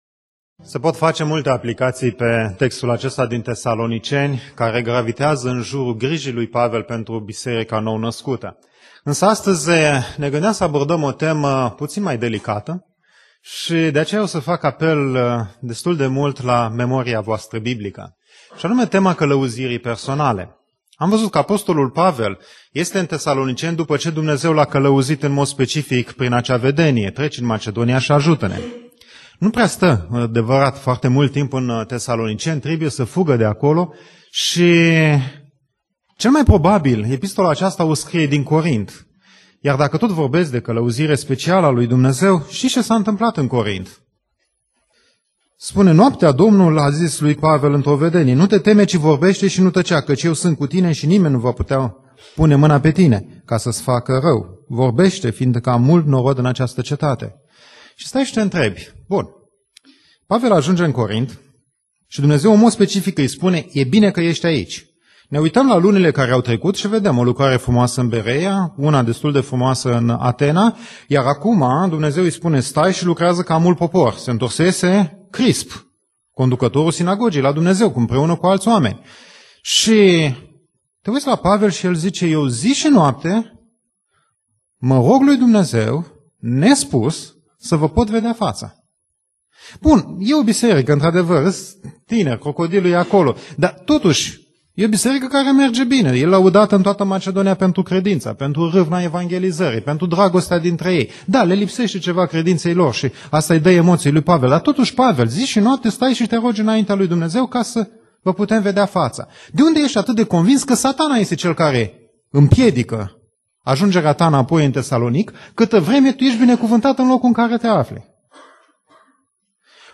Predica Aplicatie- 1 Tesaloniceni Cap.3